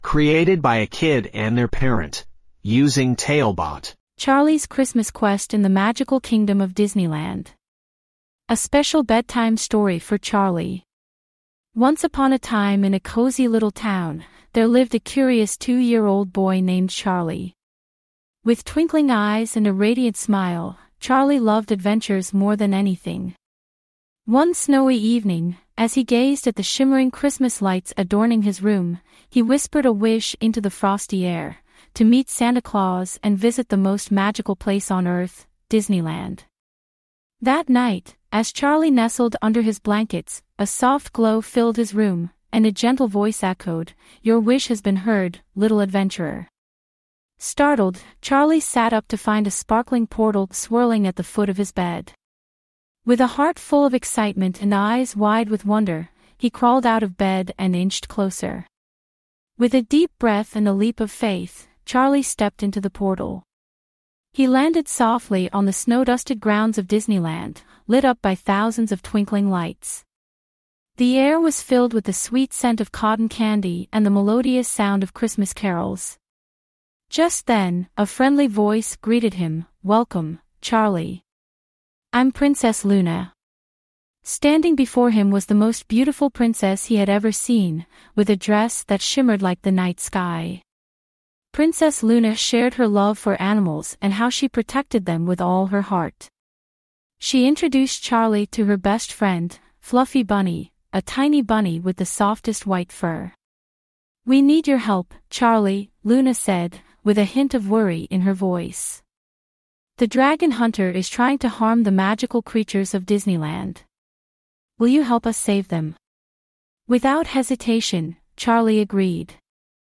5 Minute Bedtime Stories
TaleBot AI Storyteller